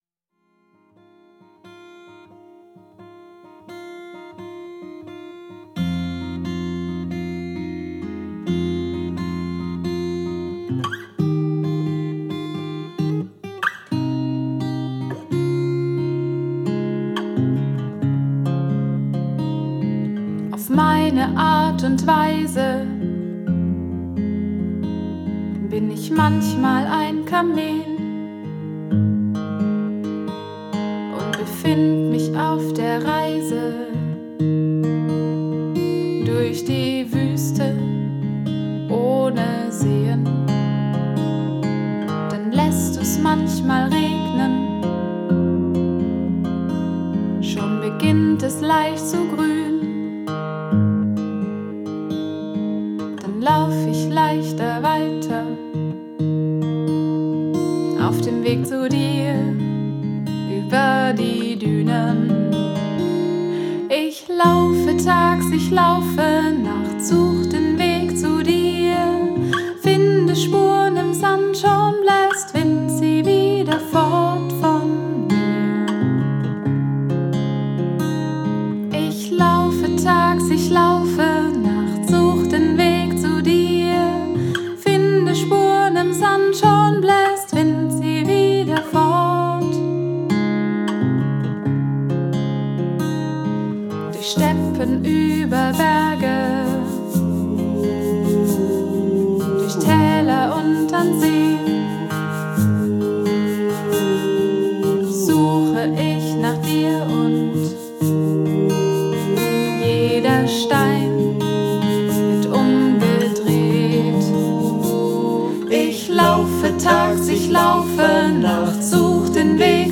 Die Songs basieren auf Gitarre und Gesang.